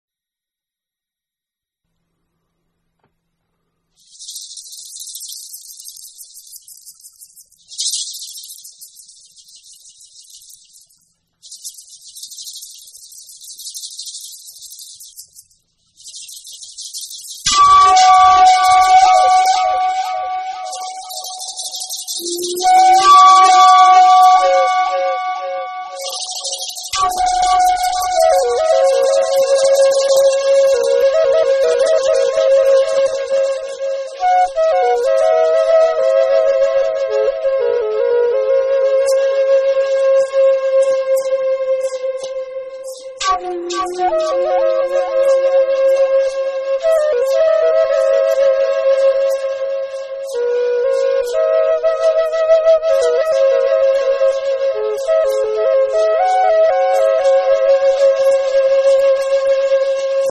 Native American Flute Music and More